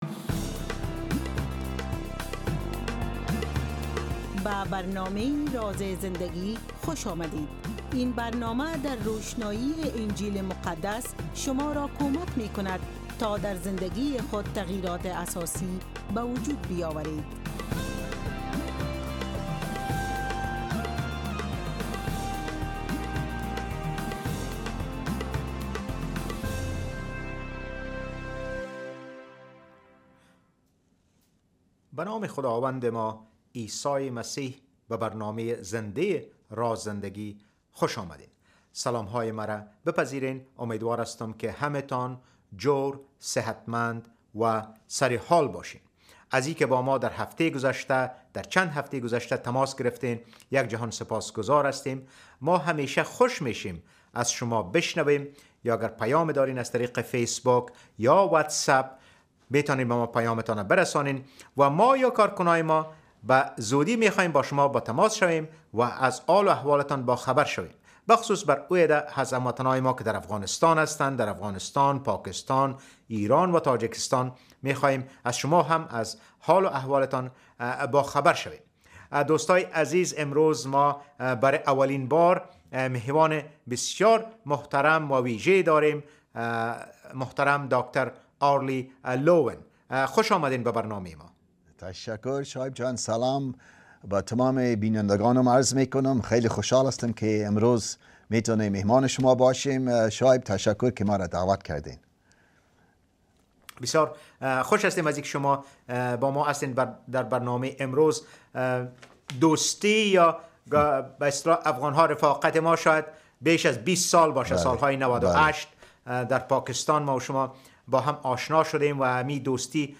درس از کتاب مقدس: خدمت در پادشاهی خدا، بخش اول: سبک‌های رهبری عیسی به ما می‌آموزد که خدمتگزار باشیم.